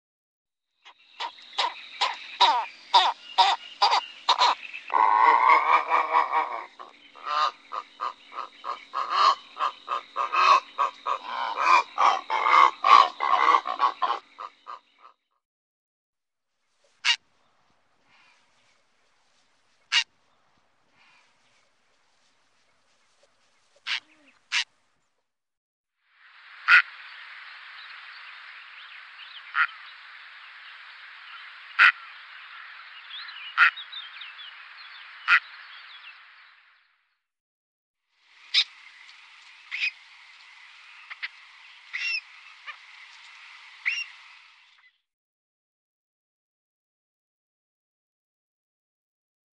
Purpurhäger / Purple Heron Ardea purpurea Läte / Sound
Purpurhager.mp3